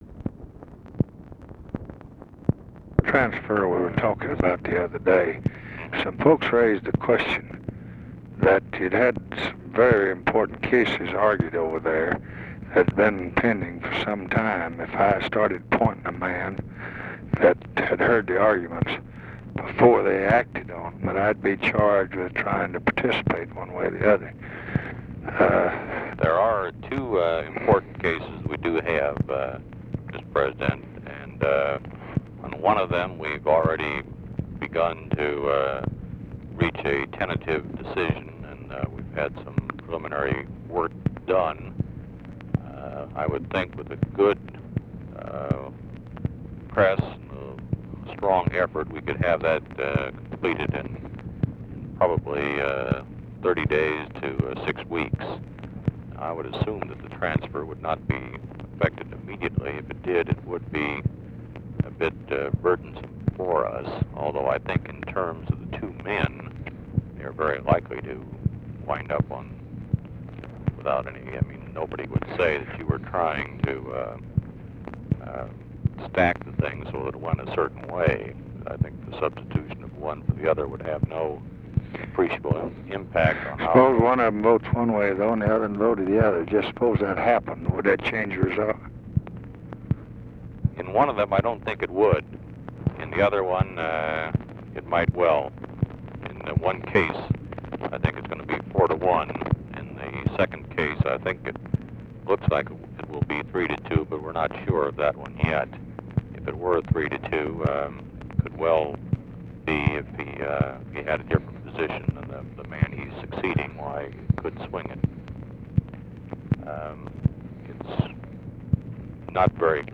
Conversation with LEE WHITE, May 2, 1966
Secret White House Tapes